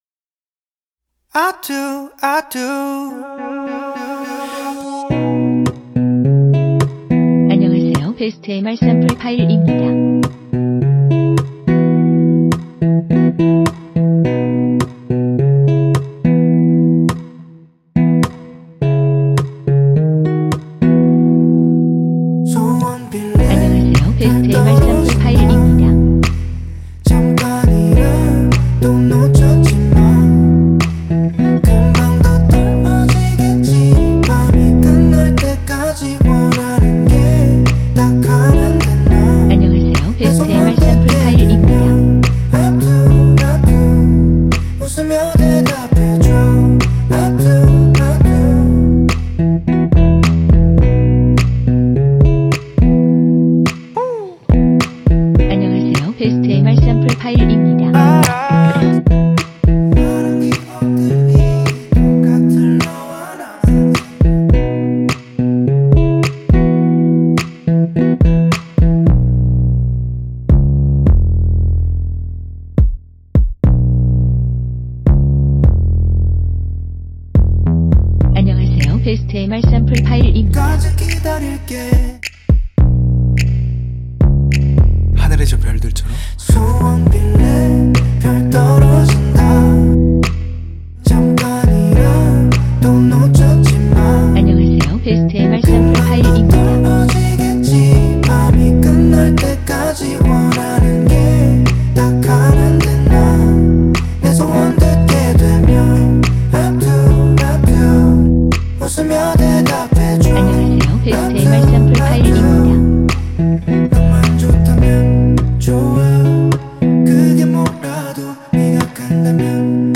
원키 코러스 포함된 MR입니다.(미리듣기 확인)